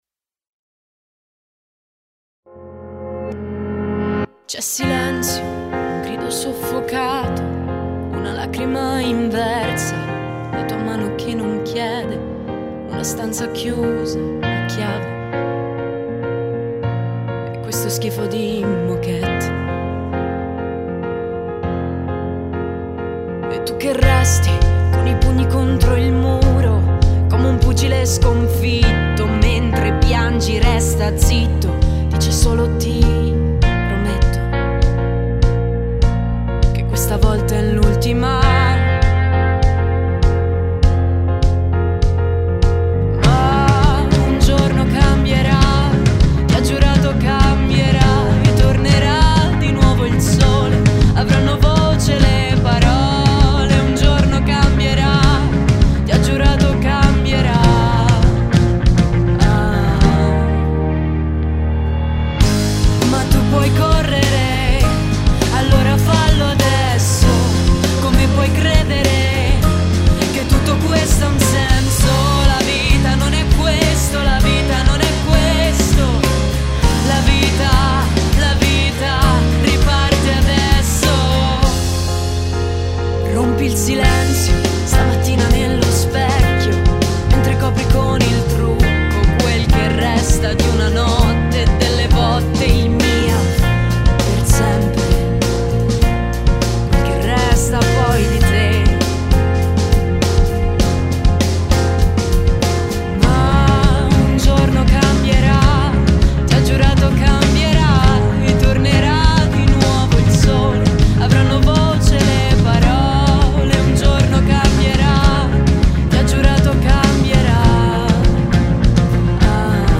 Composto ed interpretato dagli studenti
Registrato presso DAV Studio – Lucca
Lead Vox
Piano
Chitarra acustica
Chitarra elettrica
basso
batteria